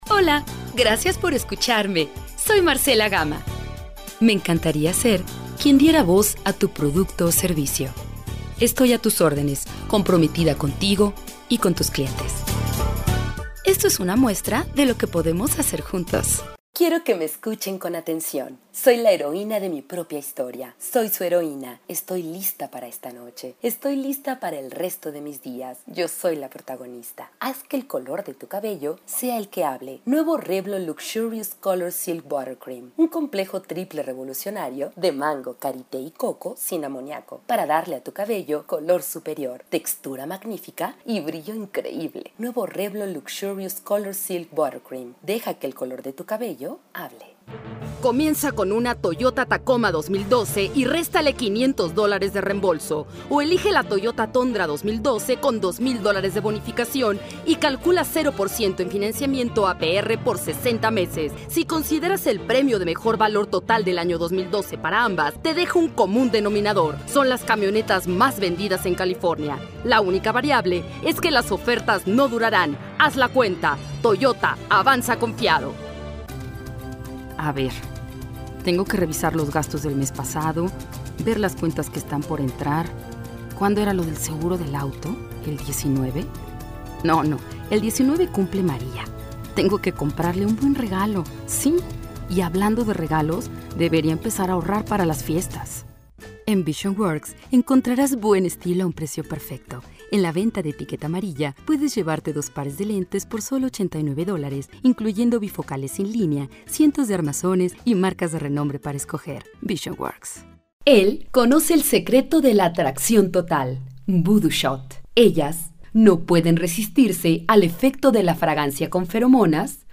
Locutora mexicana profesional
Sprechprobe: Werbung (Muttersprache):
Professional mexican announcer with experience in radio, tv spots, corporate videos and social networks